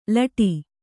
♪ laṭi